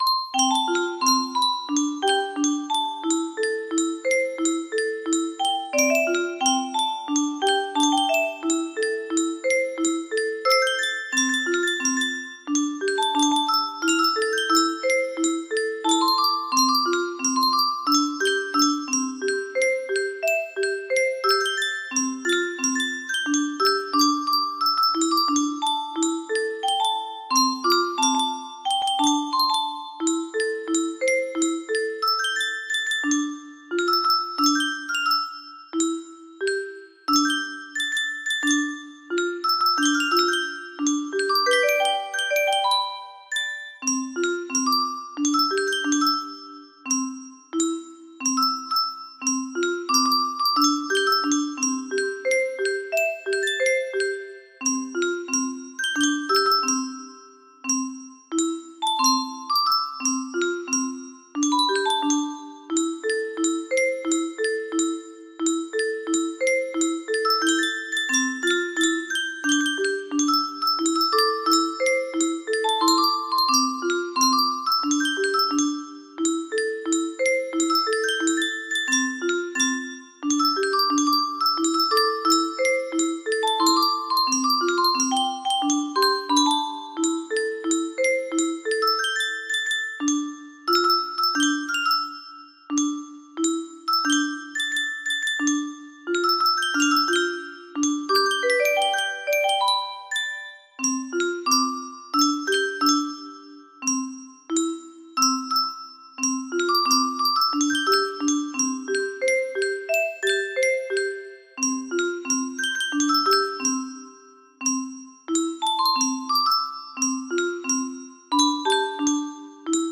Grand Illusions 20 / Muro Box 20 music boxes More